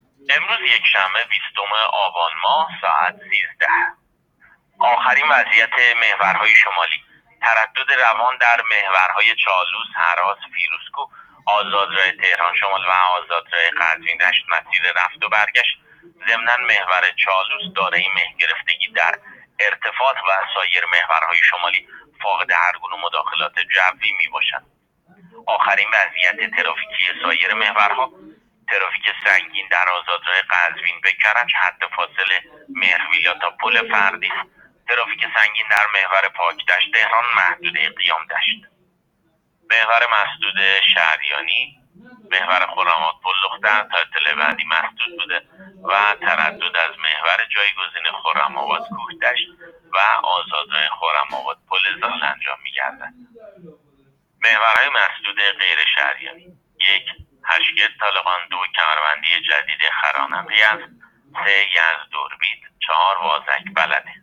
گزارش رادیو اینترنتی از آخرین وضعیت ترافیکی جاده‌ها تا ساعت ۱۳ روز ۲۰ آبان؛